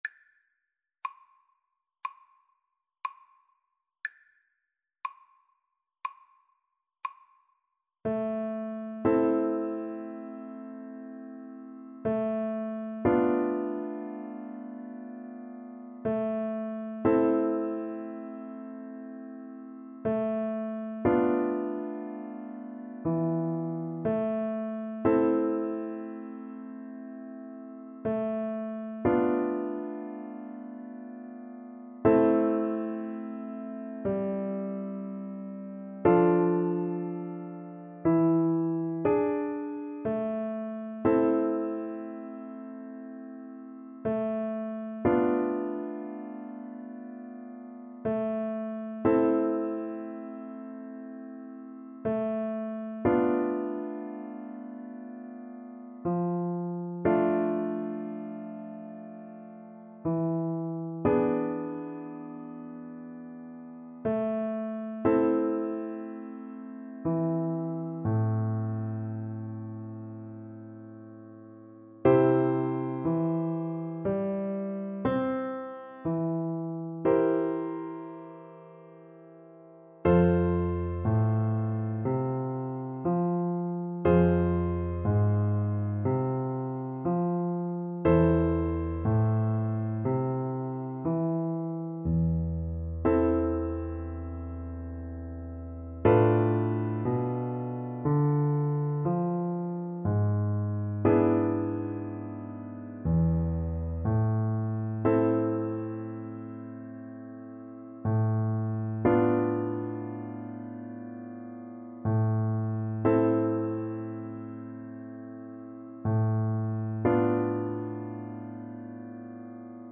4/4 (View more 4/4 Music)
C major (Sounding Pitch) (View more C major Music for Oboe )
Lento, espressivo
Pop (View more Pop Oboe Music)